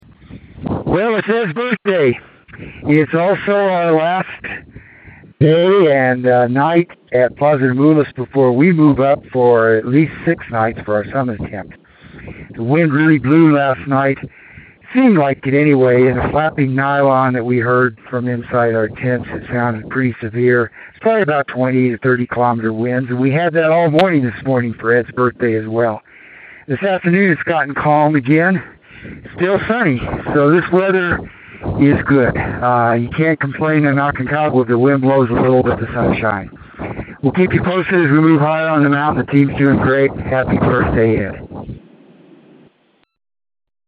Aconcagua Expedition Dispatch